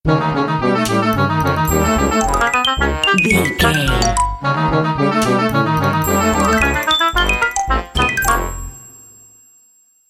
Short music, corporate logo or transition between images,
Epic / Action
Fast paced
In-crescendo
Uplifting
Ionian/Major
bright
cheerful/happy
industrial
powerful
driving
groovy
funky
synthesiser